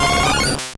Cri de Mimitoss dans Pokémon Or et Argent.